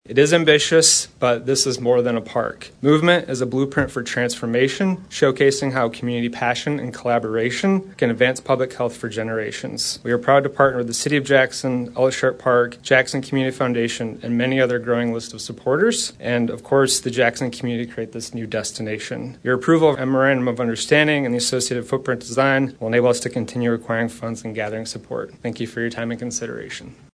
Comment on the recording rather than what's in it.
Jackson, Mich. (WKHM) — A three-phase construction project to create recreational and community gathering spaces at Ella Sharp Park was approved by Jackson City Council during their meeting on Tuesday.